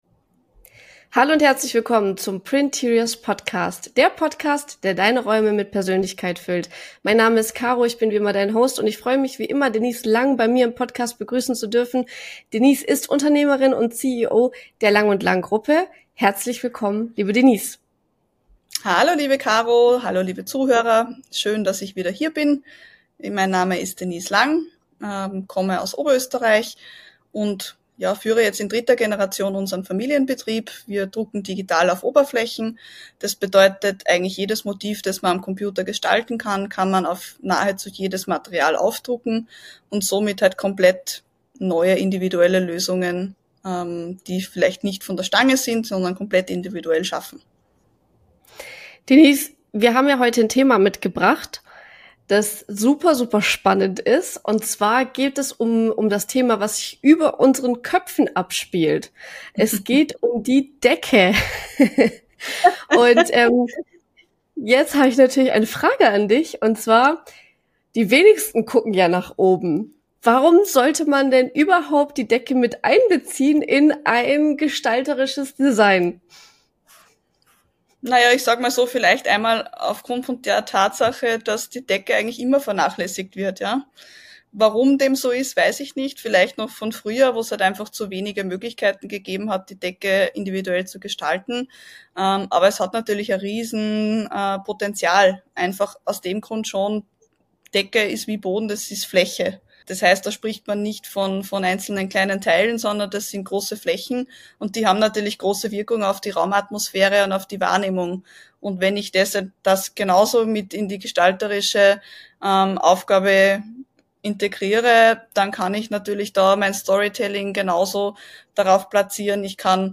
Ein Gespräch voller Inspiration für Architekten, Designer und alle, die Räume neu denken wollen.